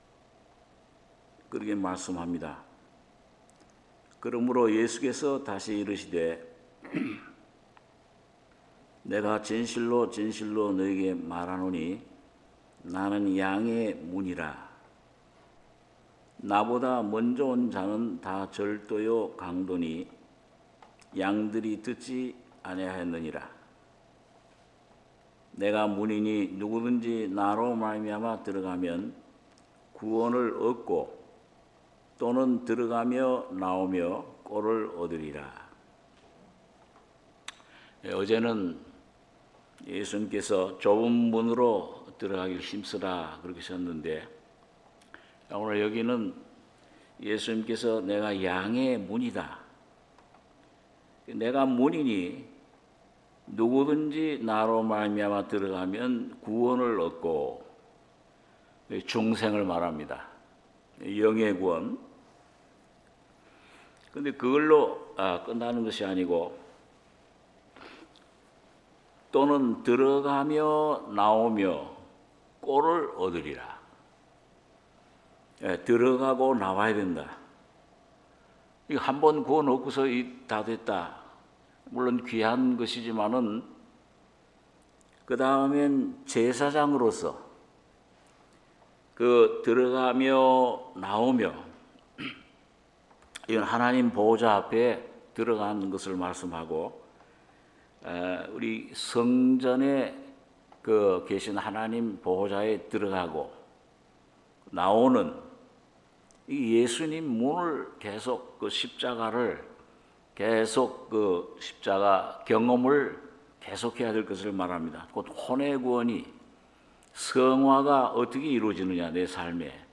특별새벽예배 요한복음 10장 7~10절